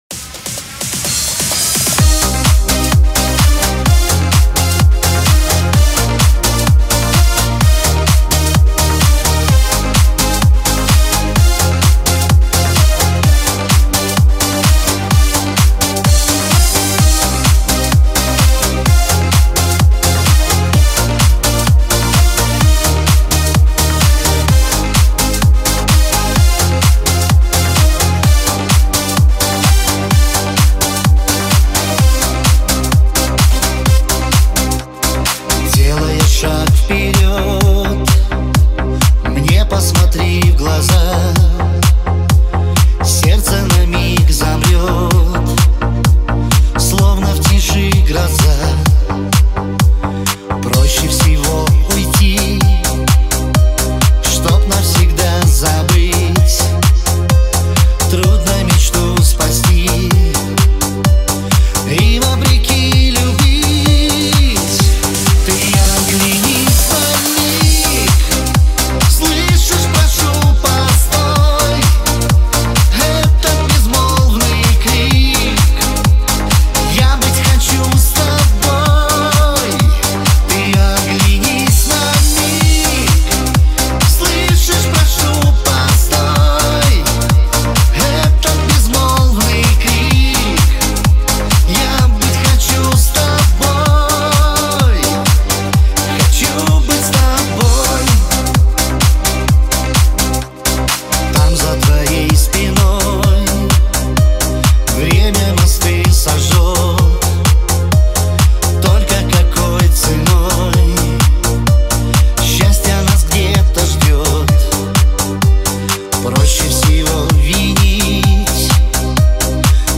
Русский шансон , шансон для танцев